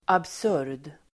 Uttal: [abs'ur:d]